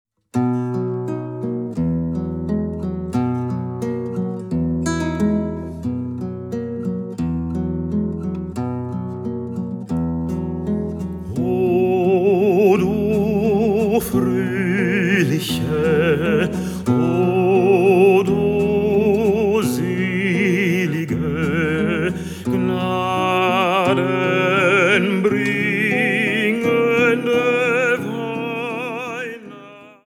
Vocal, Gitarre
Bariton
Jazz trifft klassischen Gesang